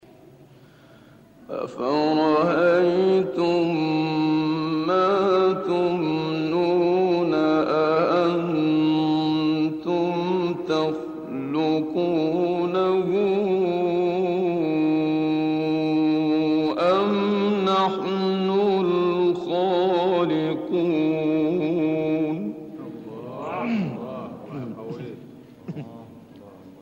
گروه شبکه اجتماعی: 10 مقطع صوتی از قاریان برجسته مصری که در مقام رست اجرا شده‌ است، می‌شنوید.
مقام رست